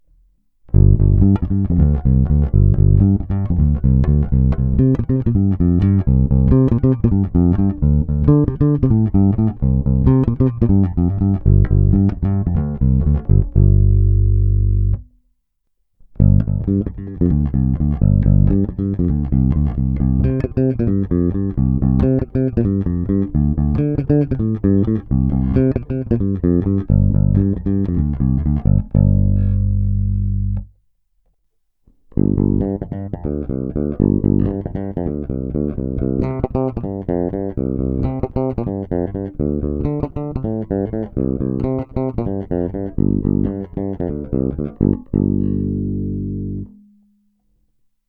Následující zvukové ukázky jsou provedeny přímo do zvukové karty a kromě normalizace ponechány bez jakýchkoli úprav.
Následující ukázky obsahují nahrávky v pořadí krkový snímač – oba snímače – kobylkový snímač: